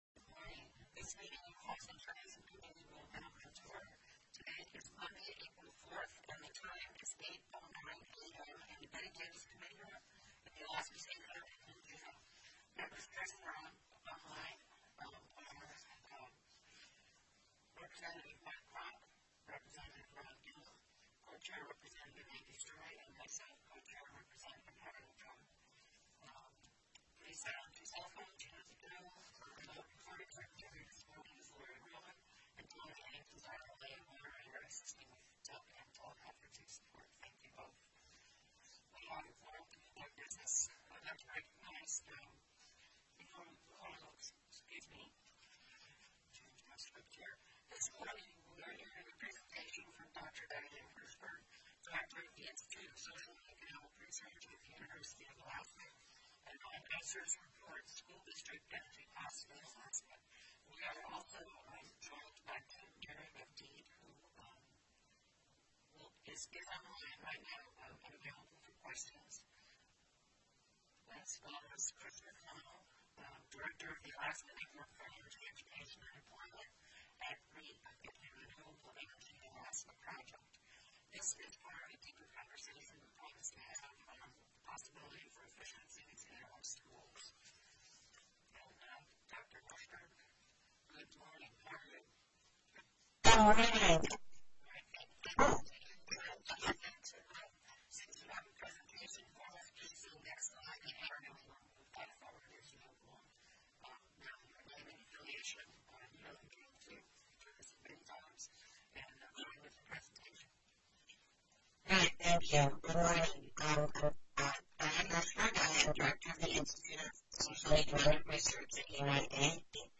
The audio recordings are captured by our records offices as the official record of the meeting and will have more accurate timestamps.
Presentation: School District Energy Costs in Alaska
Representative Tiffany Zulkosky (via teleconference)